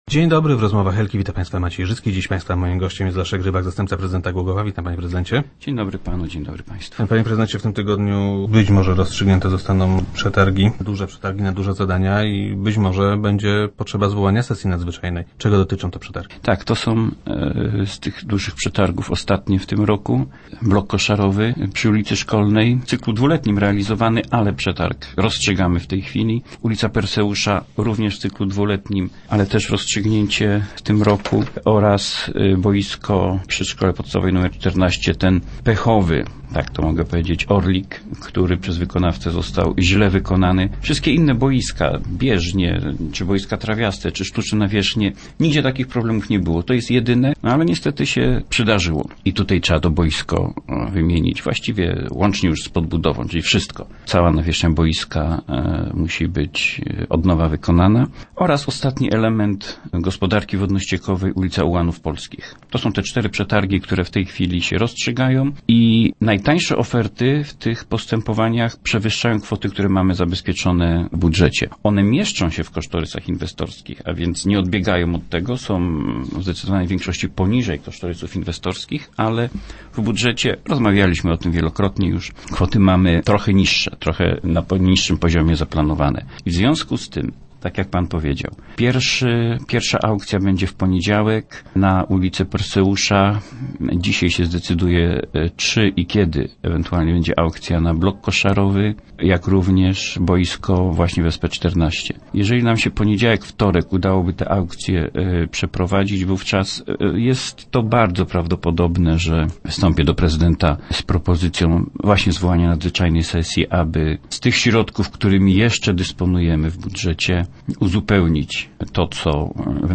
Wiele wskazuje na to, że zbyt mało pieniędzy zabezpieczono na te zadania w miejskim budżecie. - Jeśli zajdzie taka potrzeba, będę prosił prezydenta o zwołanie nadzwyczajnej sesji, żeby te zadania rozpocząć jak najszybciej – mówi Leszek Rybak, zastępca prezydenta Głogowa.